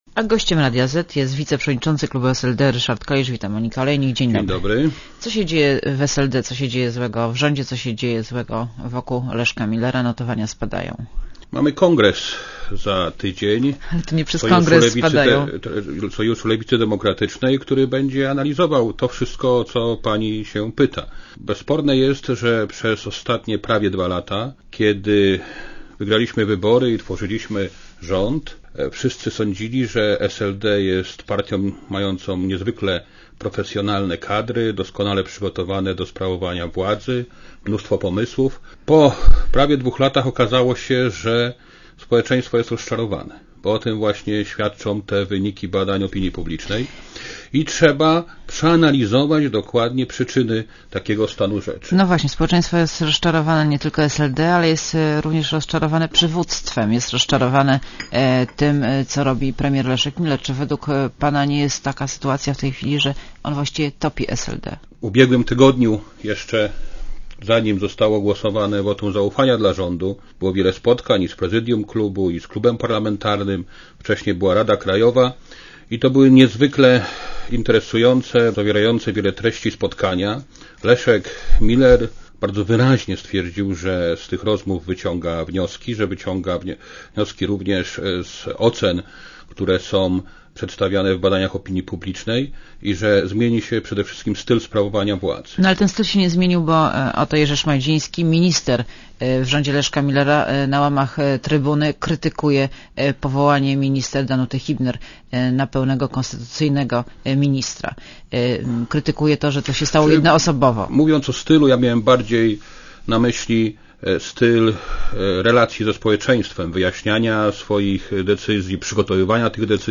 © (RadioZet) Posłuchaj wywiadu (2,3MB) Co się dzieje w SLD, co się dzieje złego w rządzie, co się dzieje złego wokół Leszka Millera?